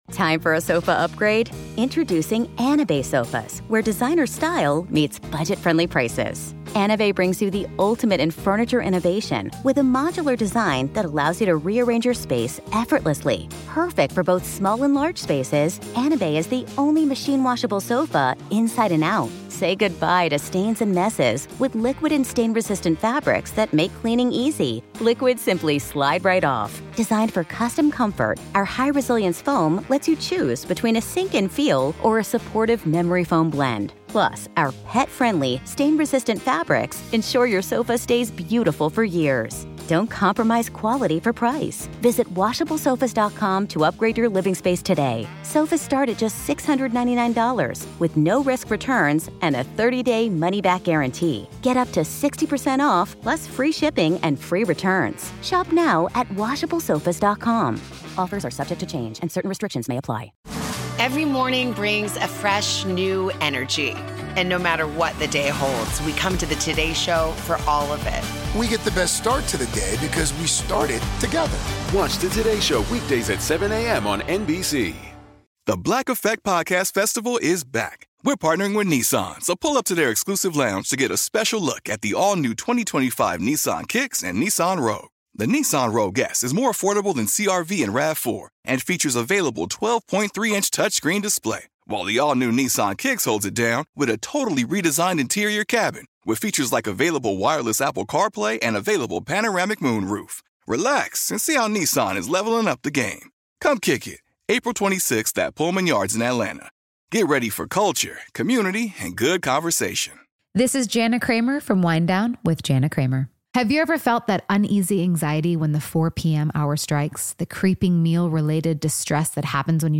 We'd like to thank the Atlanta History Center for allowing us access to this audio, originally part of their Veterans History Project.